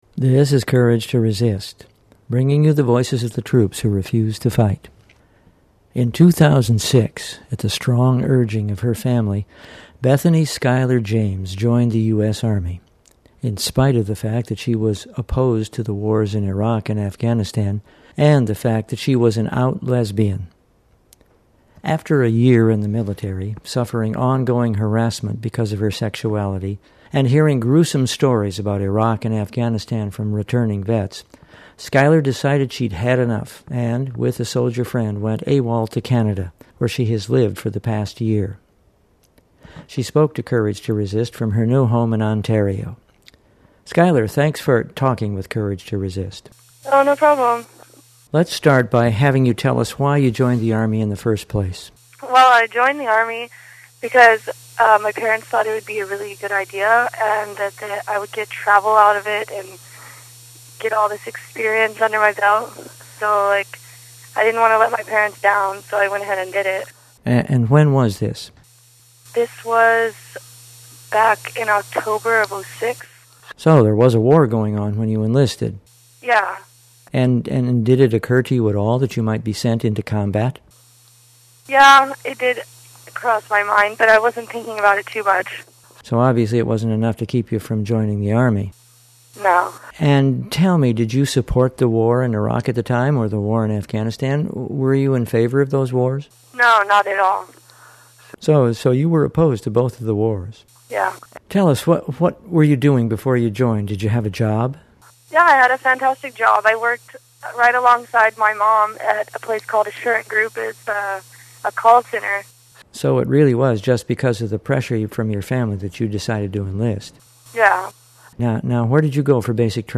She spoke to Courage to Resist from her home in Ontario. 10:33